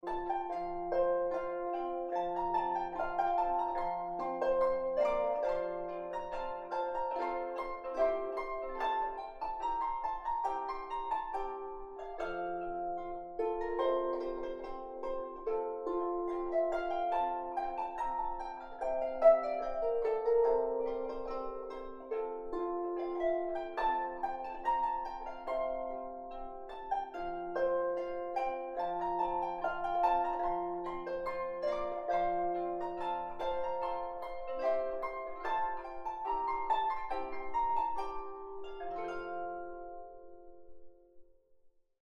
Rondeau (Mouret) (harp)